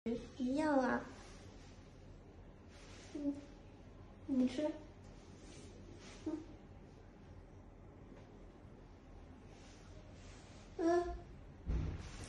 Cat#cat sound effects free download